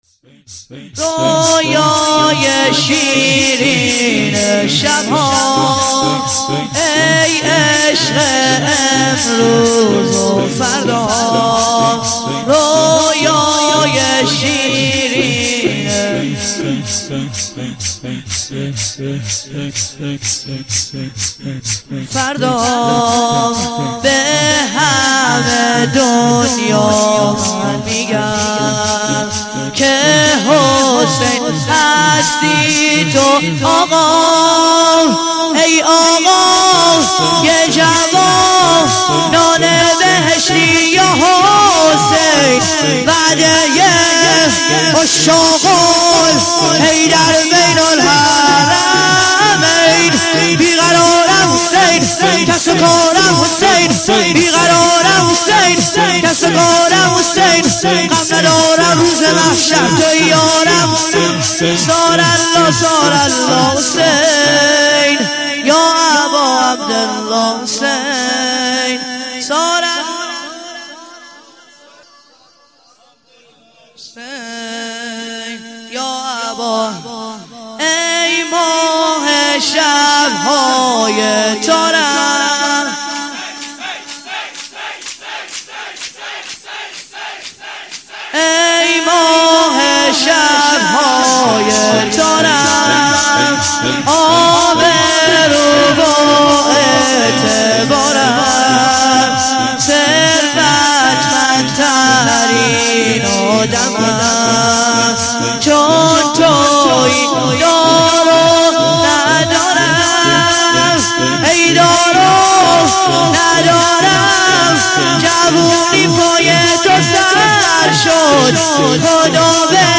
شور - رویای شیرین شبهام